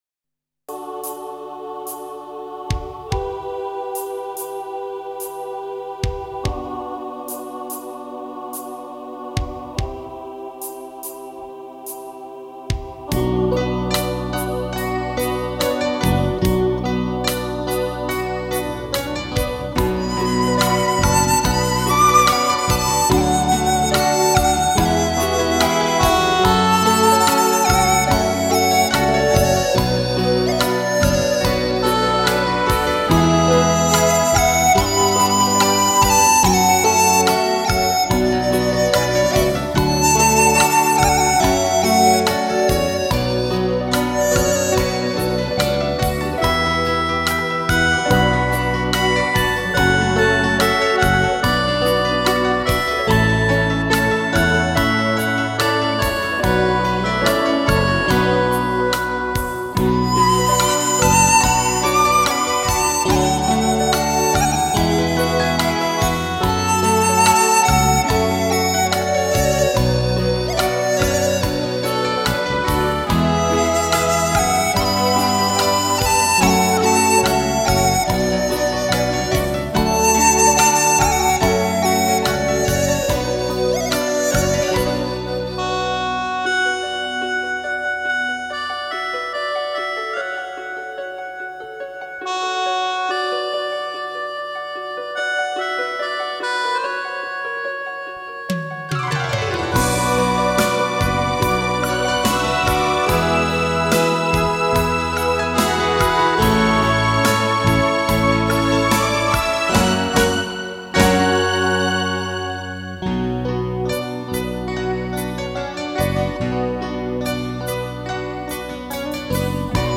一段乐曲足已把人引到一个世外桃源,尽享民族风情,如乘风驾云赏月,沁人心脾.
巴乌